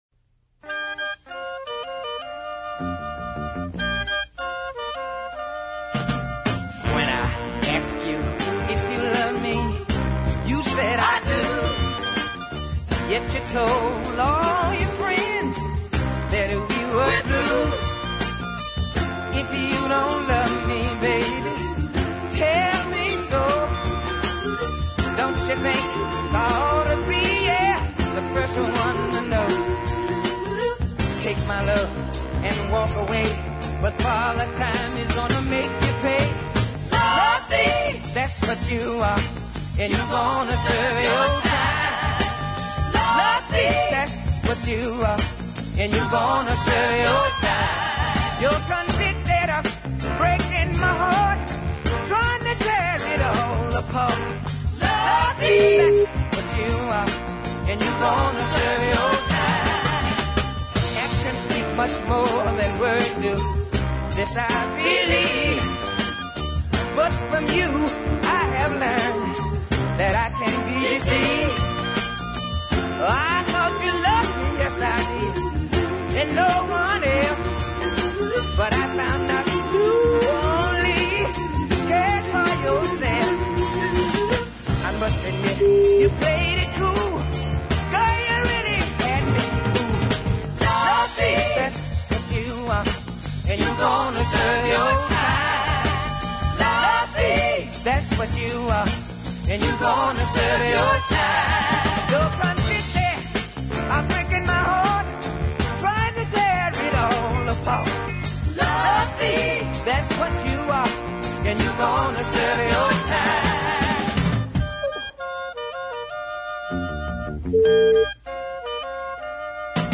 His other first class track is the similarly paced